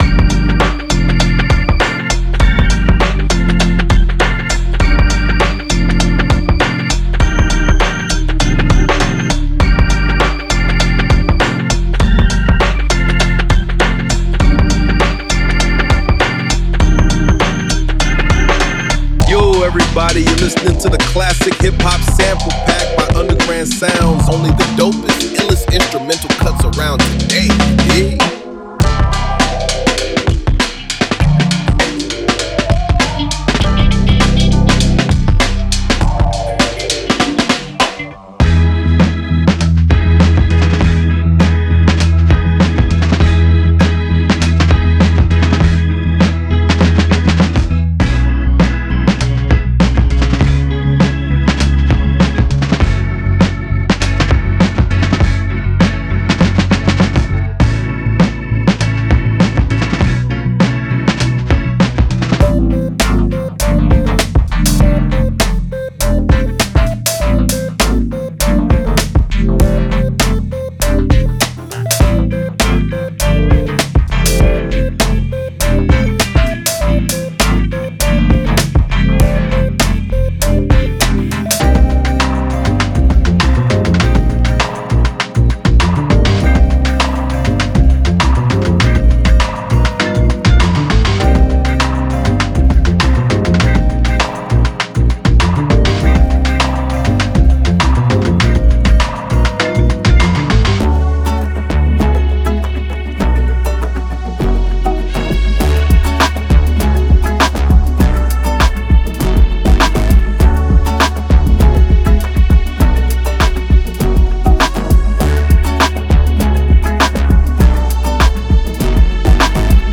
Drum Loops → ヒップホップの鼓動とも言えるのが、埃っぽくスウィング感のあるドラムブレイク。
Music Loops → デモに収録されたすべてのトラックを、ミックス＆マスタリング済みの状態で収録。